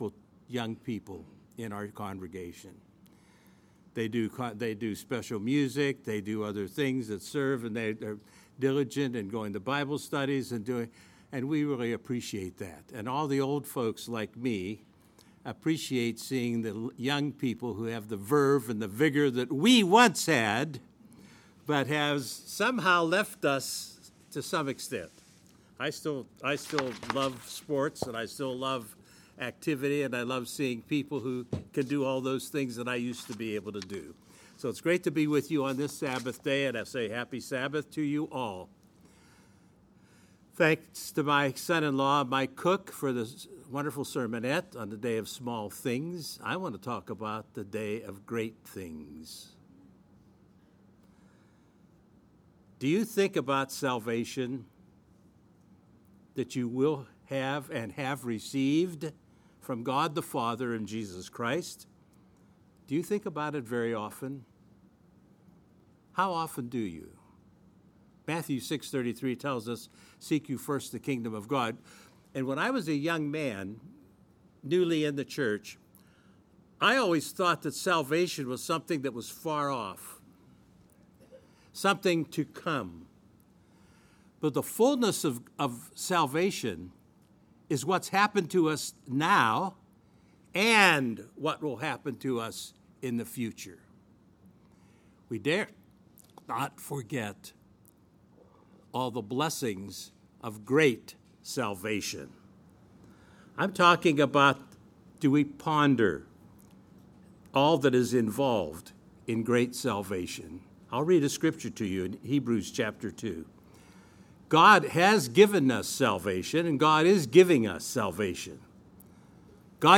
[NOTE: Apologies for the audio issue. The audio begins around 2 minutes in.]